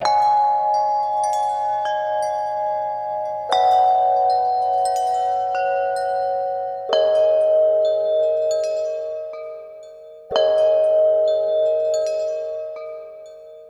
Chime Chords.wav